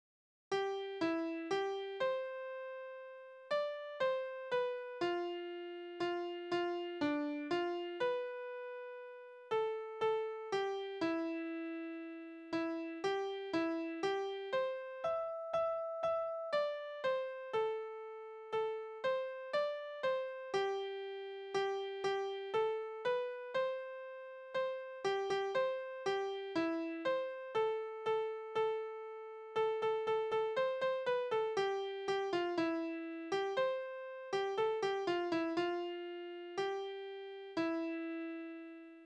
Tonart: C-Dur
Taktart: 4/4
Tonumfang: große None
Besetzung: vokal